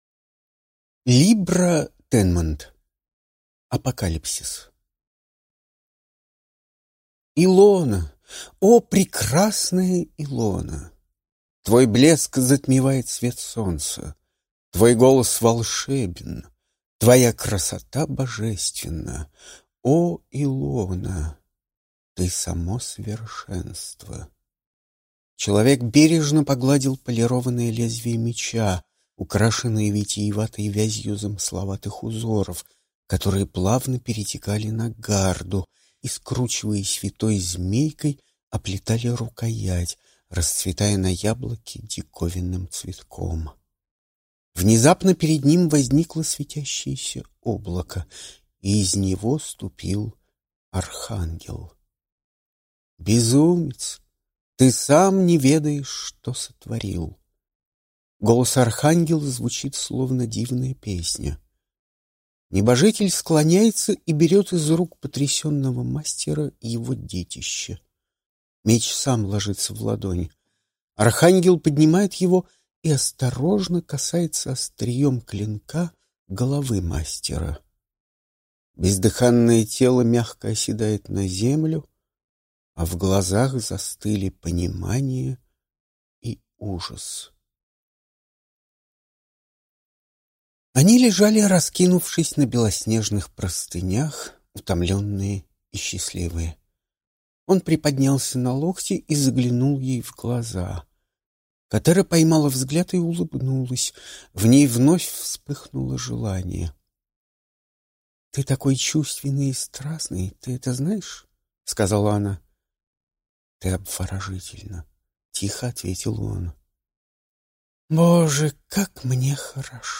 Аудиокнига Апокалипсис | Библиотека аудиокниг